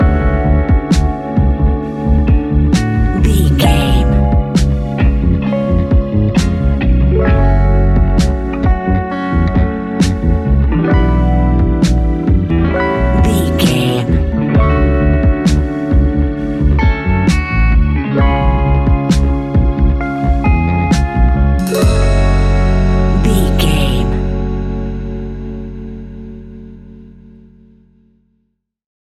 Ionian/Major
F♯
laid back
Lounge
sparse
chilled electronica
ambient
atmospheric